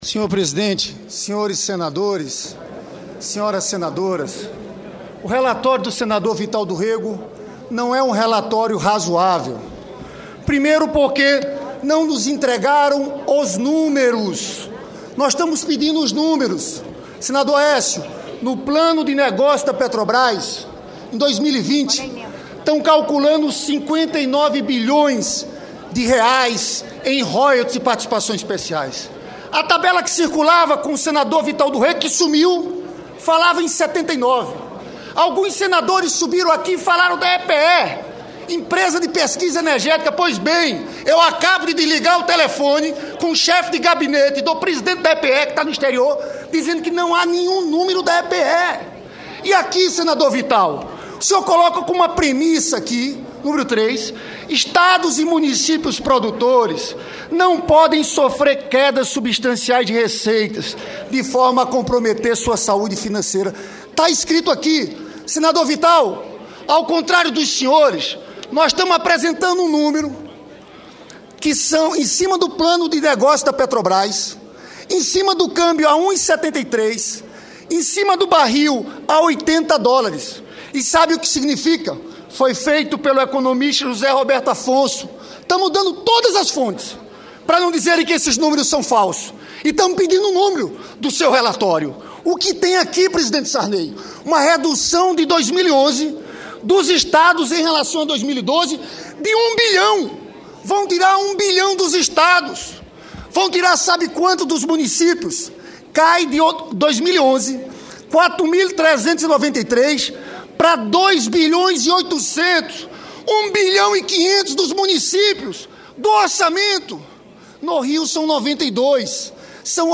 Pronunciamento do senador Lindbergh Farias